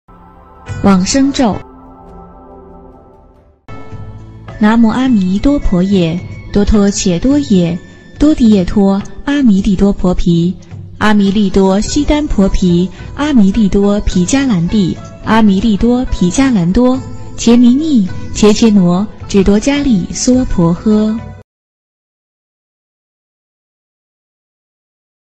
音频：经文教念-《往生净土神咒》